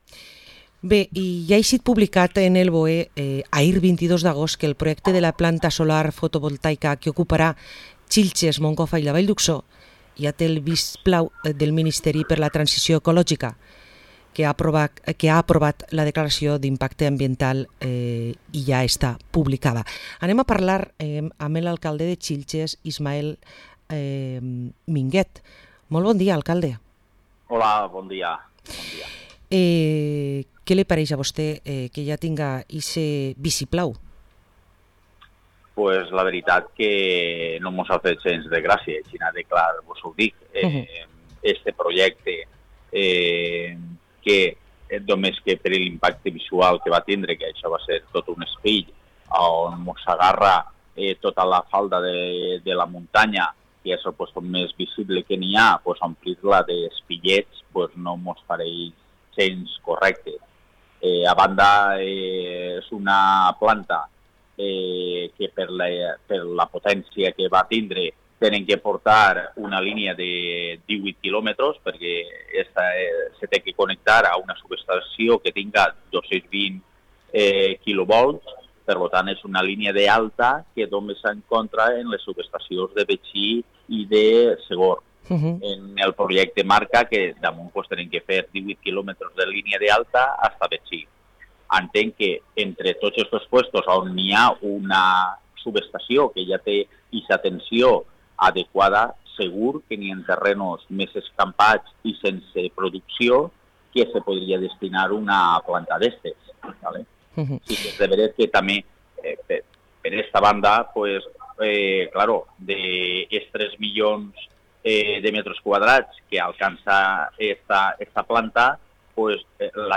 Parlem amb Ismael Minguet Teresa, alcalde de Xilxes